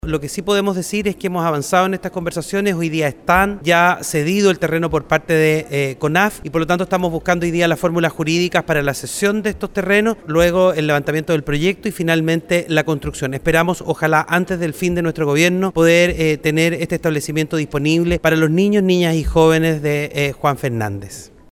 Así lo expusieron diversas autoridades ministeriales y locales ante la Comisión de Educación de la Cámara de Diputadas y Diputados, la que fue citada con el objetivo de presentar los avanzas en esta materia.
En dicha instancia, el ministro de educación, Marco Ávila, señaló que han trabajado en conjunto con la cartera de Bienes Nacionales y la Conaf para concretar el traspaso de los terrenos.